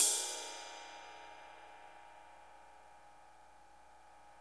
CYM XRIDE 5E.wav